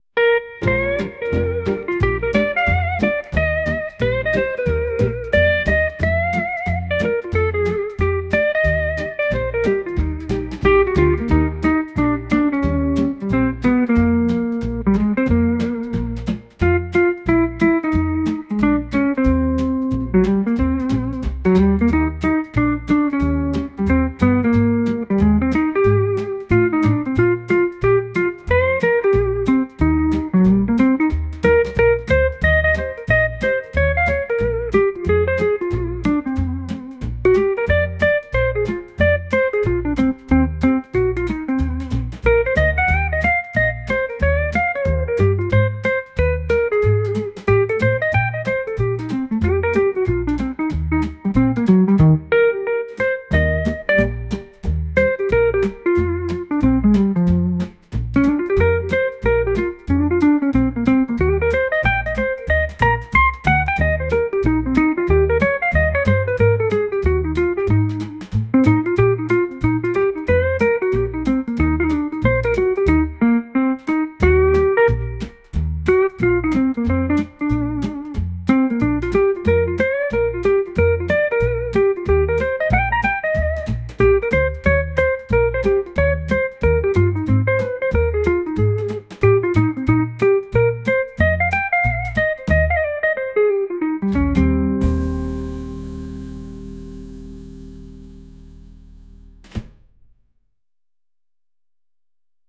ジャンルボサノバ
楽曲イメージゆったり, ムーディー, , 日常, 爽やか